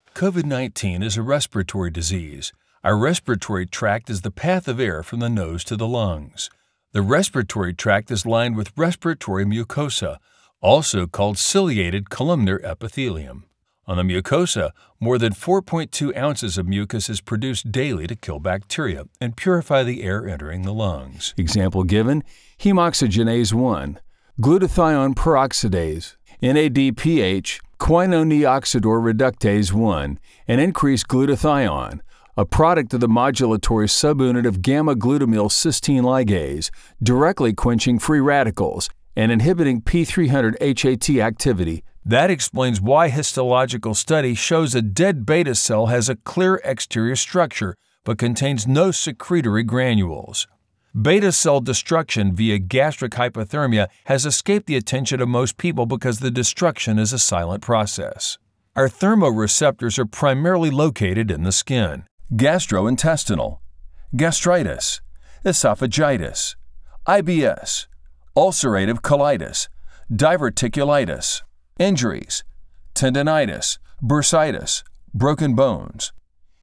Powerful, deep male Voiceover with over 27 years experience in all kinds of projects
Medical Narration
Texan Southwestern